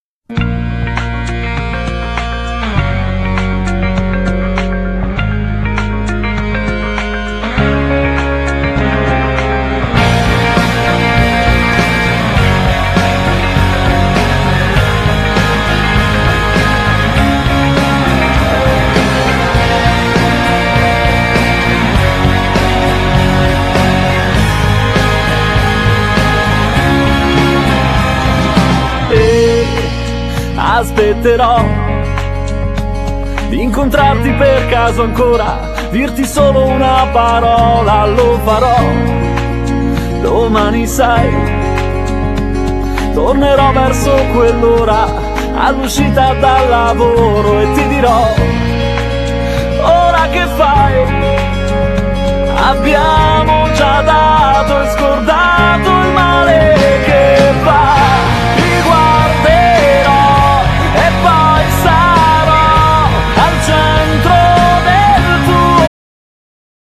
Genere : Pop
un brano intenso e diretto